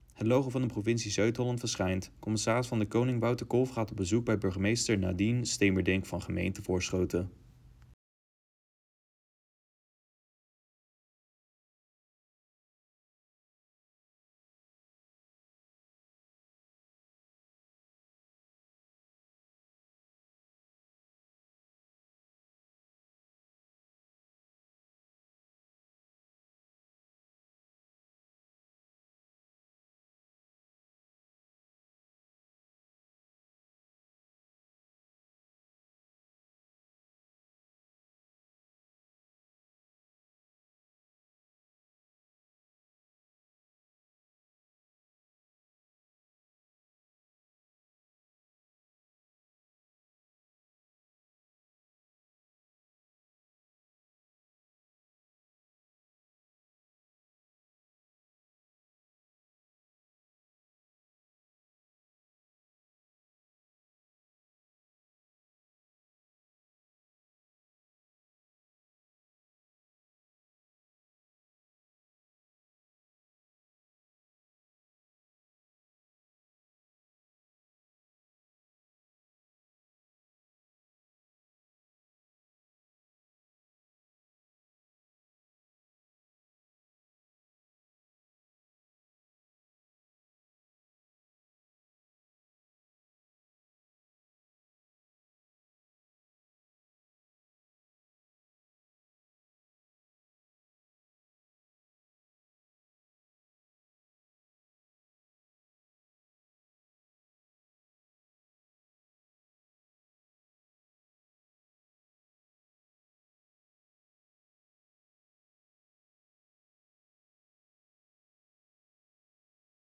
CdK in gesprek met de burgemeester van Voorschoten.